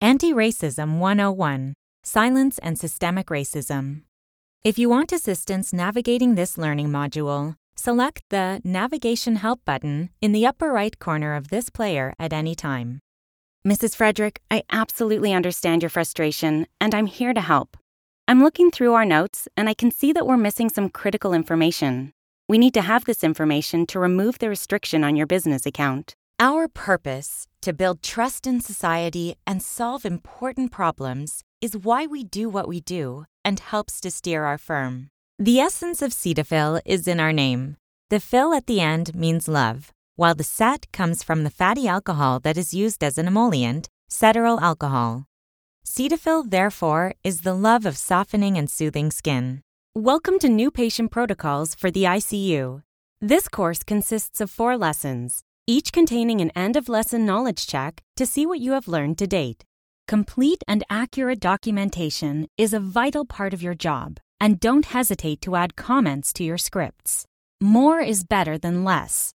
English (Canadian) voice actor